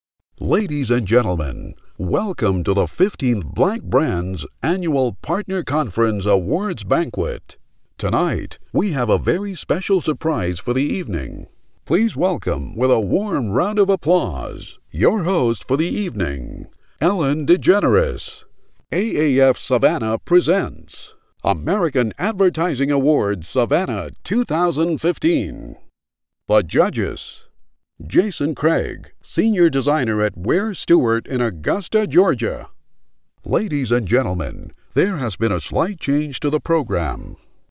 Male
Adult (30-50), Older Sound (50+)
Warm, deep, smooth, authoritative, trailers, edgy, conversational, classic, calming, professional, corporate, natural, smooth, mellow, mature, honest, sincere, trustworthy, serious, soothing, commanding, firm, educational, masculine, husky, dramatic, serious, gruff, epic, gravelly, burly.
Live Announcer Awards Ceremony